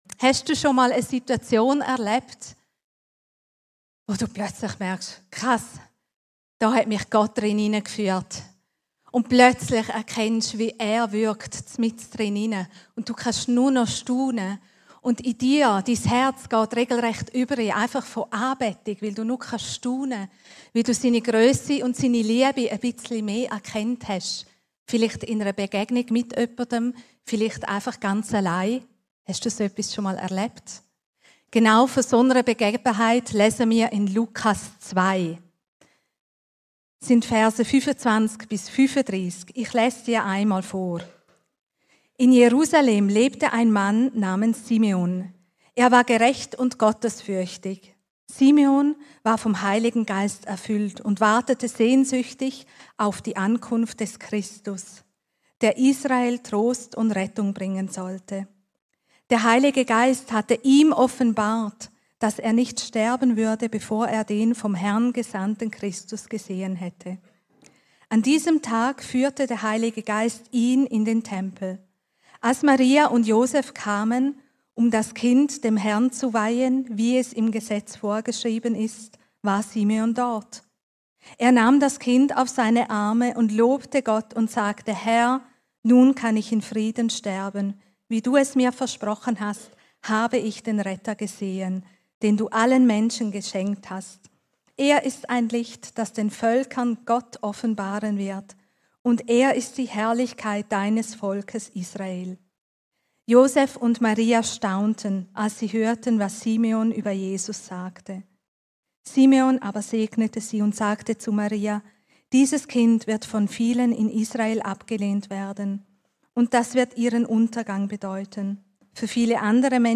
Weitere Predigten